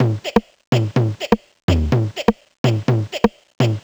cch_jack_percussion_loop_patch_125.wav